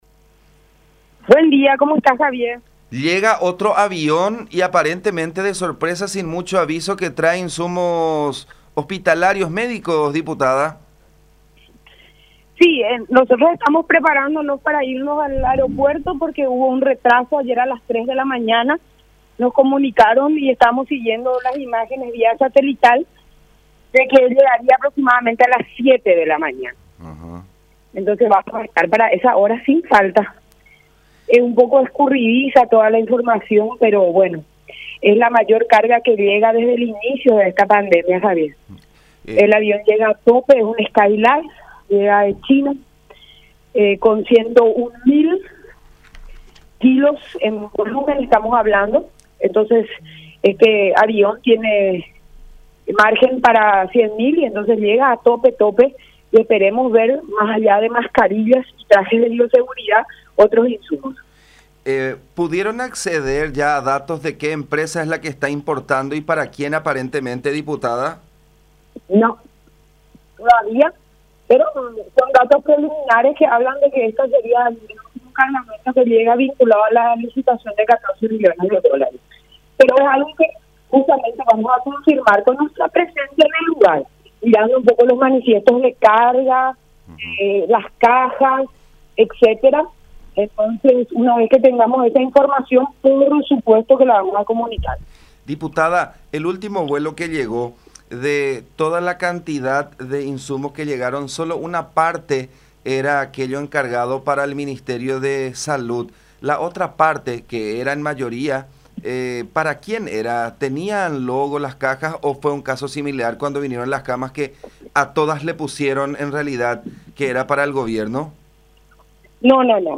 “La aeronave llega a tope, tiene margen para 100.000 kilos de elementos. Llega de China”, dijo la diputada González, del Partido Encuentro Nacional (PEN), en diálogo con La Unión, indicando que para las 07:00 horas se prevé la llegada.